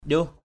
/ʄuh/ (d.) củi = bois de chauffage. njuh thait W~H =ET củi tươi = bois vert. njuh thu W~H E~% củi khô = bois sec. njuh baok W~H _b)K củi mục...